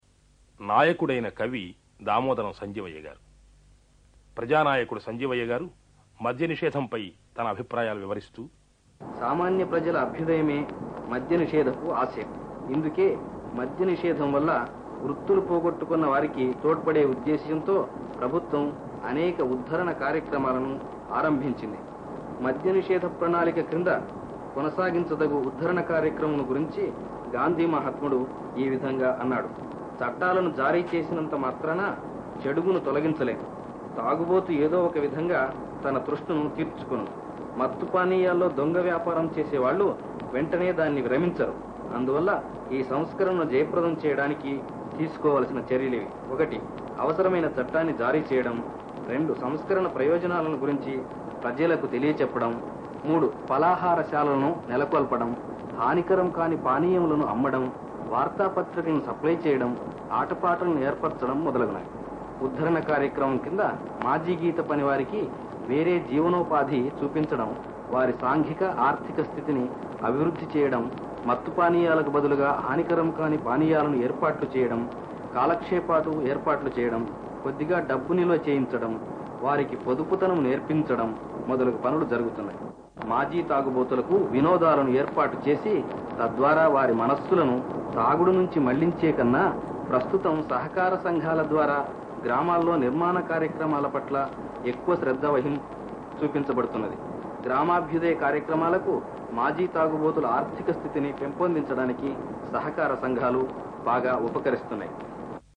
ఆడియో అంతగా బాలేకపోయినా వినడానికి ఇబ్బంది కలిగేంతగా లేదు.
కవి, ప్రజానాయకుడు అయిన దామోదరం సంజీవయ్య మద్యనిషేధంపై చేసిన ప్రసంగం నుంచి (నిడివి 1ని. 45క్ష.).